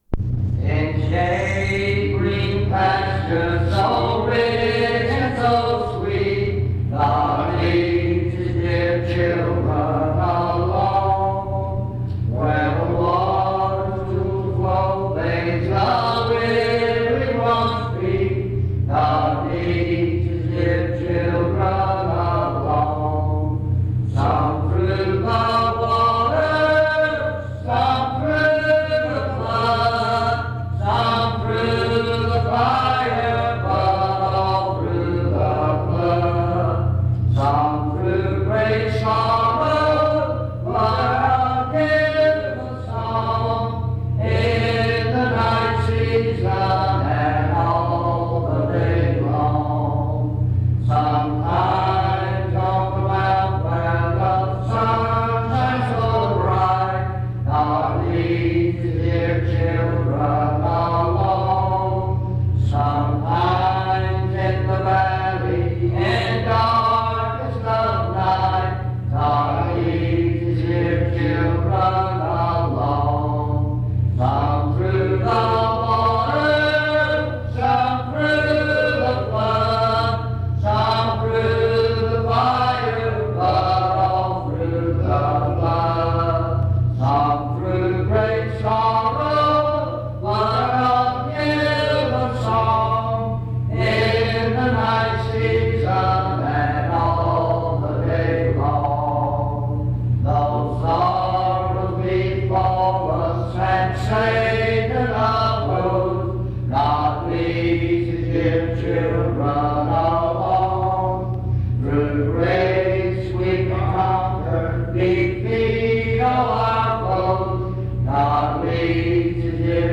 In Collection: Reidsville/Lindsey Street Primitive Baptist Church audio recordings Miniaturansicht Titel Hochladedatum Sichtbarkeit Aktionen PBHLA-ACC.001_087-B-01.wav 2026-02-12 Herunterladen PBHLA-ACC.001_087-A-01.wav 2026-02-12 Herunterladen